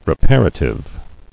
[re·par·a·tive]